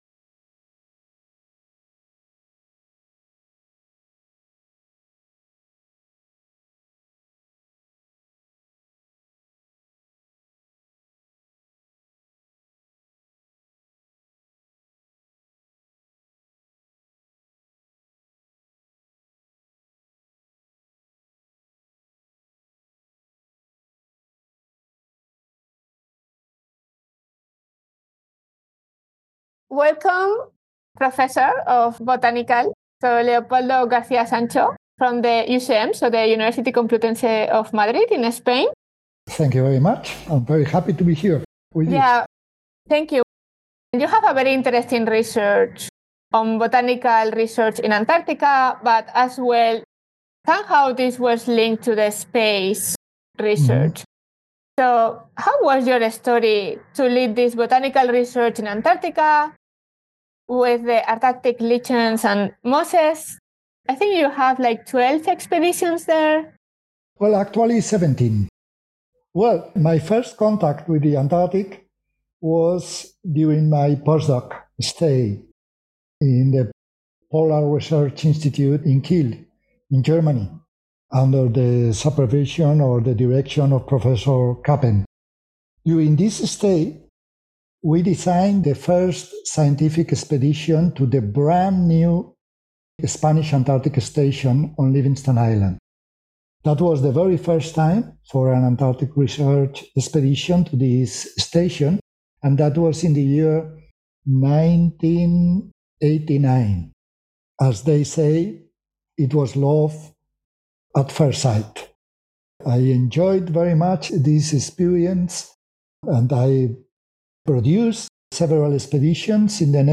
We delve into the first-ever astrobiology experiment using lichens, which demonstrated their resilience in the harsh conditions of outer space. Additionally, he explains advanced techniques for measuring photosynthesis, highlighting their importance for field research. This conversation uncovers how Antarctic science can inspire applications that benefit both Earth and space exploration.